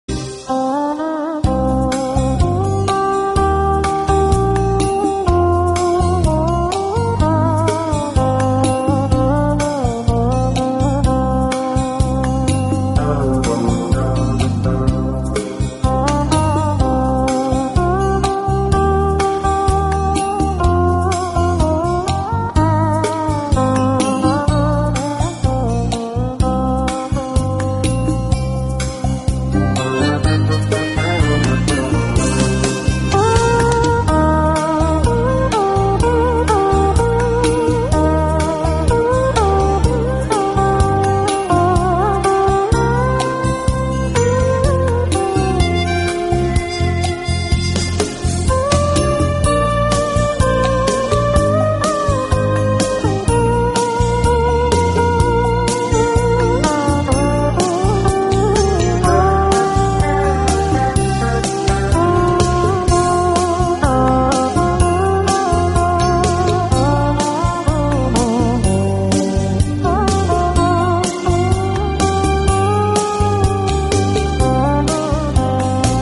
Không Lời) – Tiếng Đàn Bầu